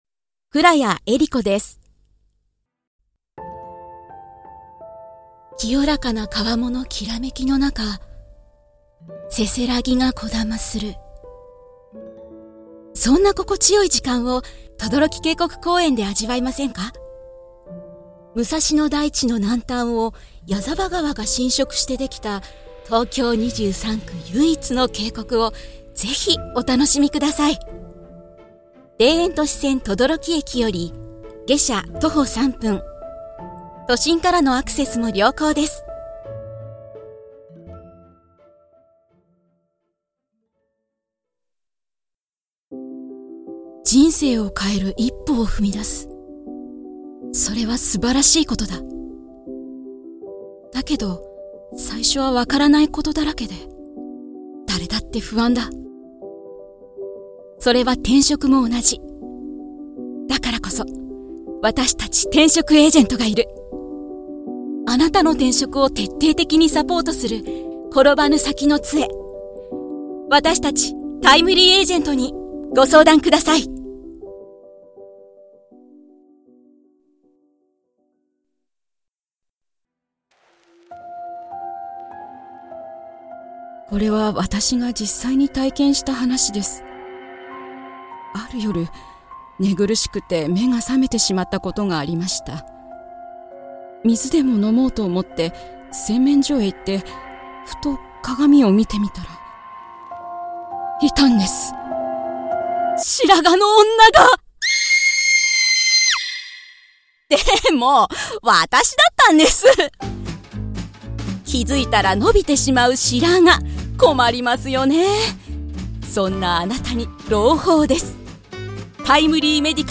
まっすぐでコミカルな声